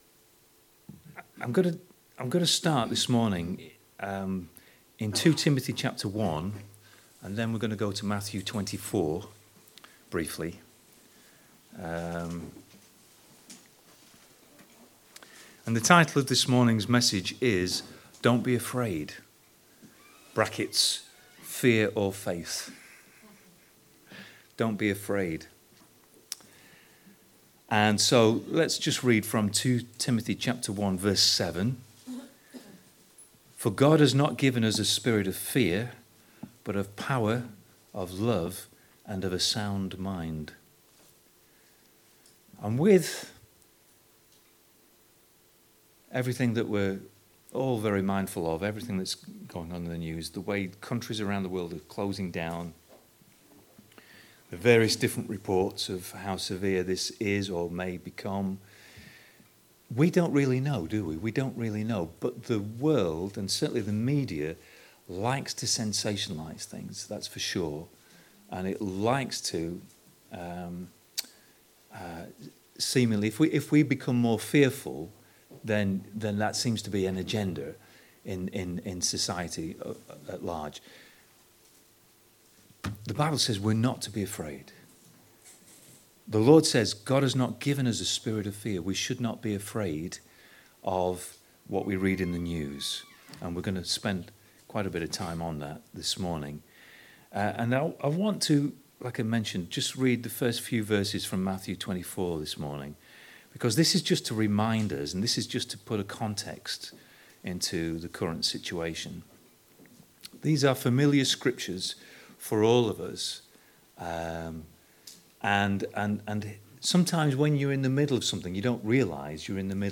Once again welcome to our online service from this morning in our church fellowship.